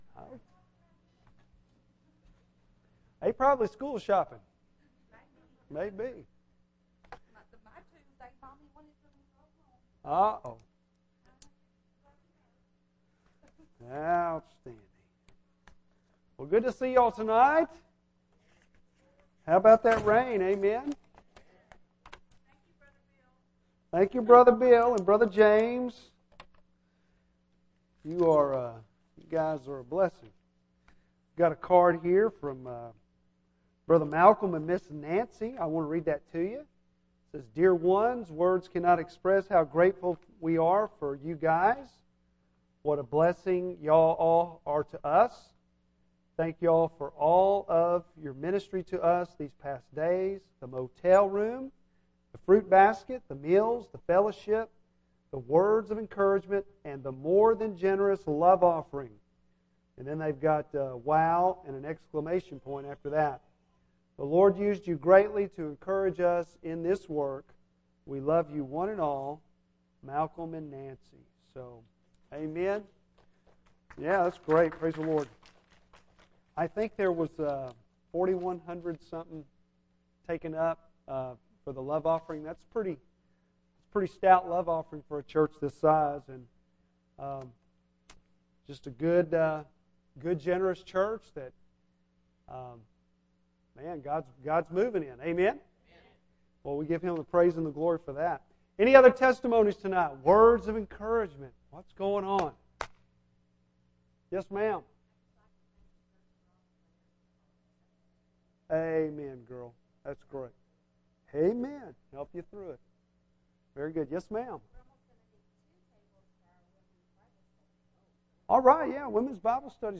Bible Text: Genesis 30:25-36 | Preacher